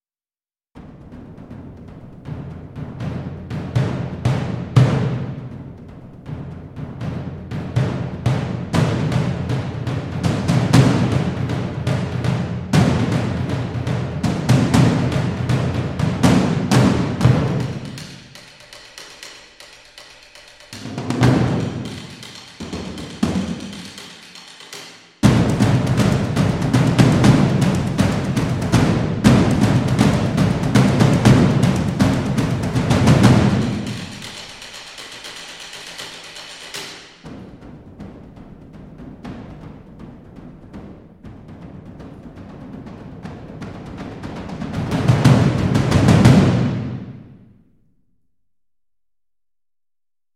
【独家 | 打击乐风格KONTAKT扩展】Aria Sounds咏叹调鼓和打击乐套装
Aria Sounds架子鼓和打击乐器包包含Aria Sounds的3个最好的架子鼓和打击乐器库。
包括的图书馆有Surdos和Snares，催化剂和巨大的Toms